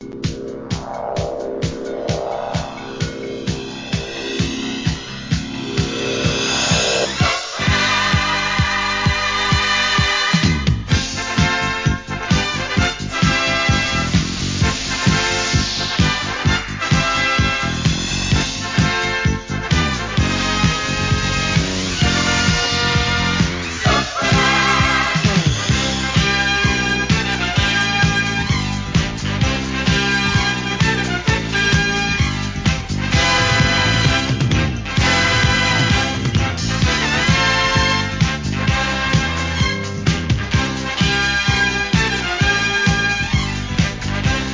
¥ 550 税込 関連カテゴリ SOUL/FUNK/etc...
お得意のディスコ・アレンジ！！